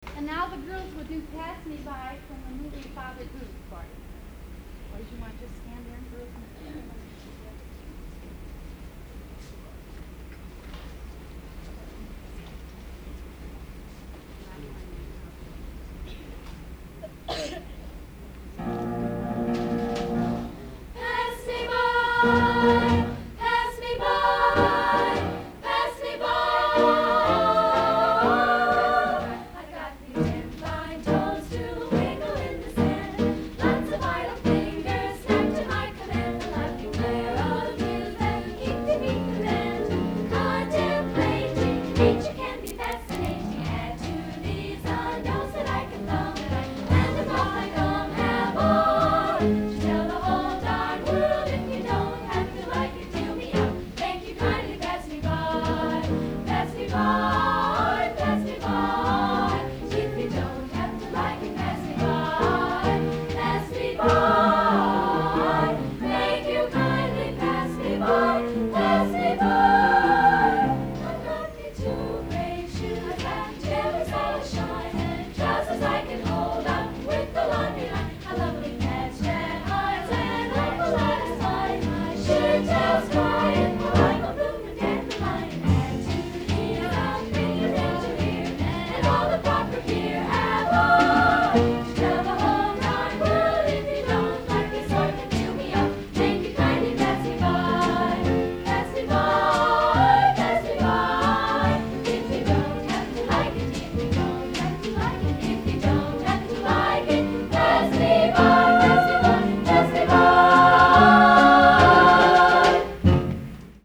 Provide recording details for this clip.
Recorded at the Elks Club for St Joseph County Nurses Assoc.